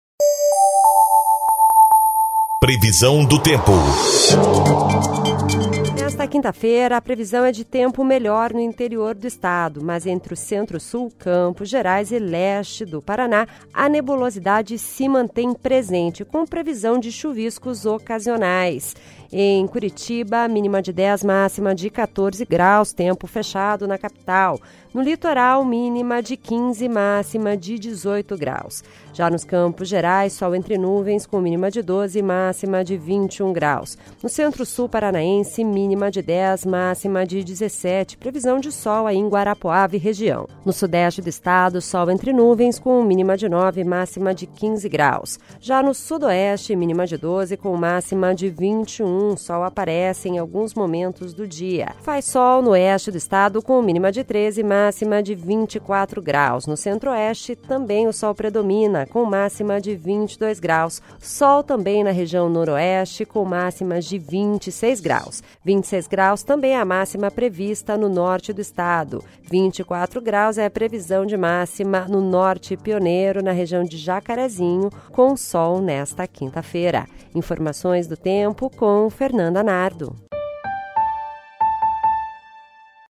Previsão do Tempo (20/07)